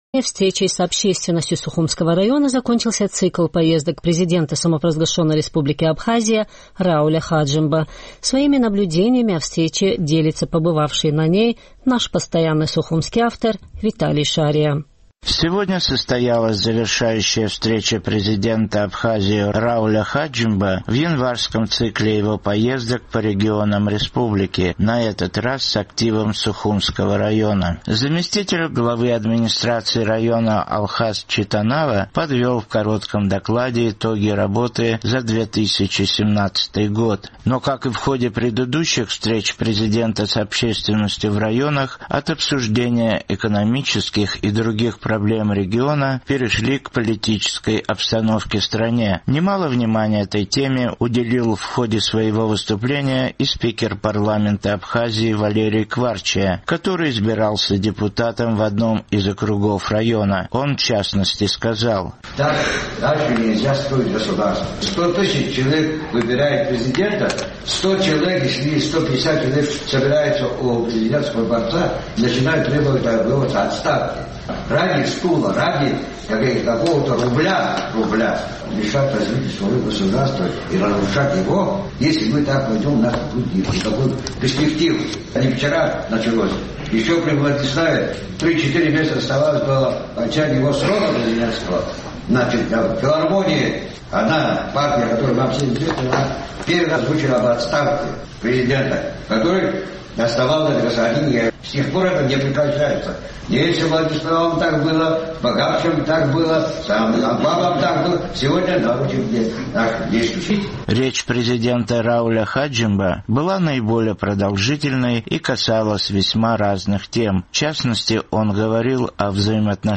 Речь президента Рауля Хаджимба была наиболее продолжительной и касалась весьма разных тем
Сегодня состоялась завершающая встреча президента Абхазии Рауля Хаджимба в январском цикле его поездок по регионам республики, – на этот раз с активом Сухумского района.